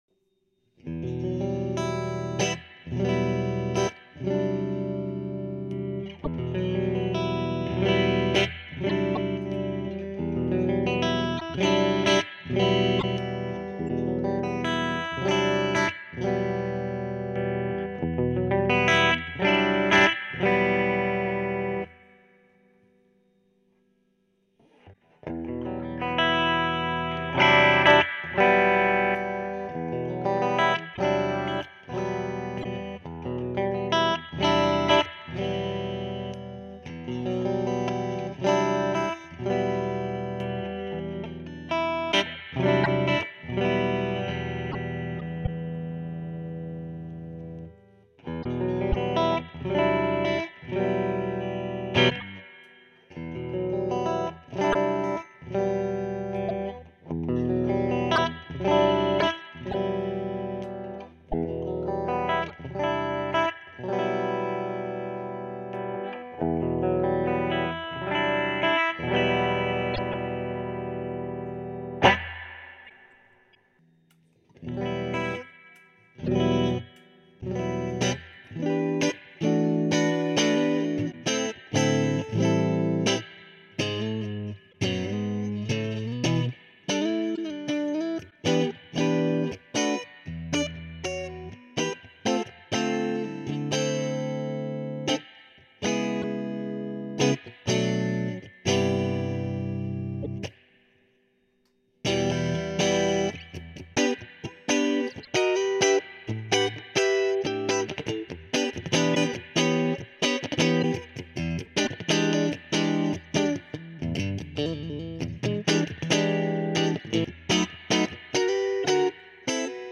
HP Celestion : Sonne comme les meilleurs marshall mais en transo ! Très typé JCM800, mais en plus domptable et plus pratiquable à faible volume ..
Reverb12 Clean.mp3